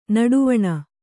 ♪ naḍuvaṇa